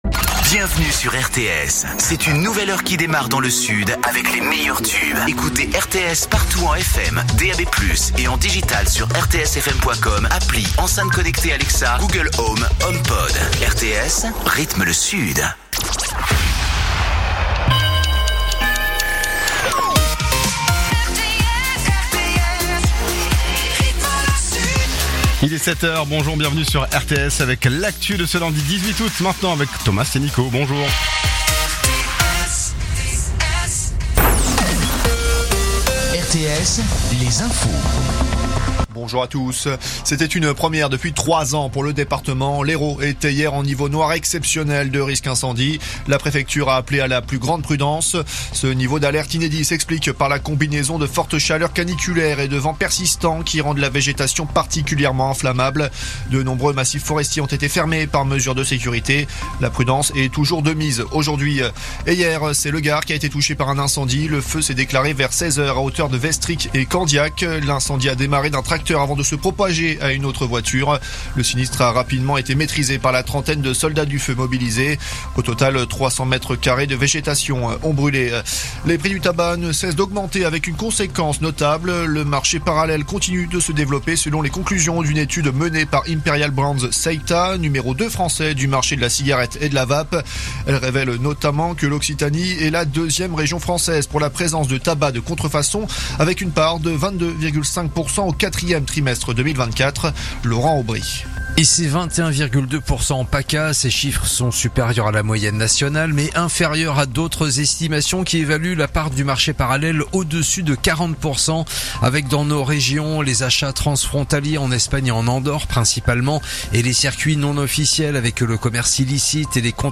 Écoutez les dernières actus de Perpignan en 3 min : faits divers, économie, politique, sport, météo. 7h,7h30,8h,8h30,9h,17h,18h,19h.